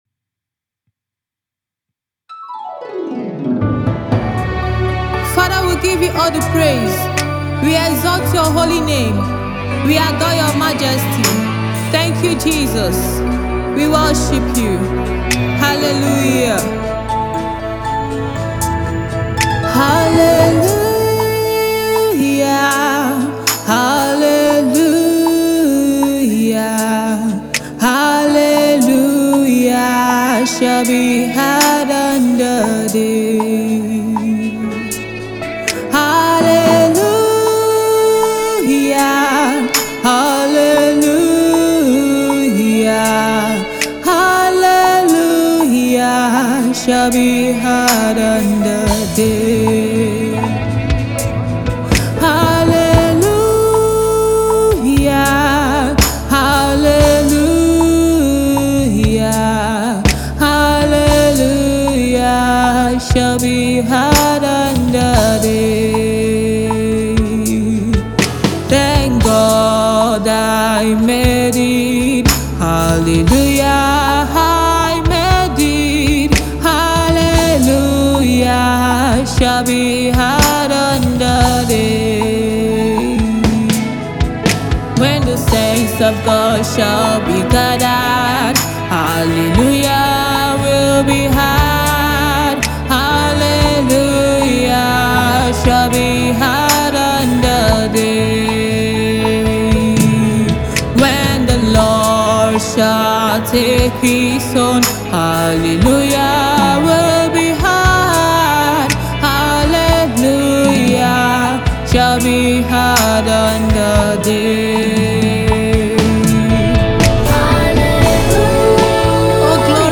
Here is a song by gospel minister